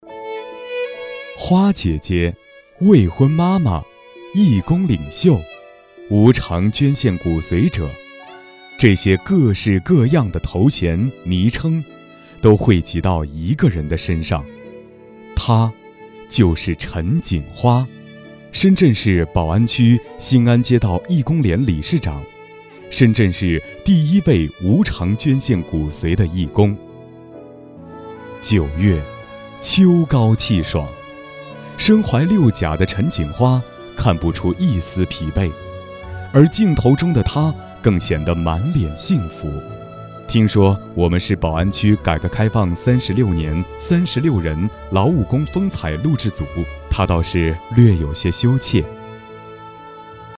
人物男159号（人物)
娓娓道来 人物专题
声音中音偏浑厚，擅长专题片、宣传片、党政宣传片、课件、新闻播报等。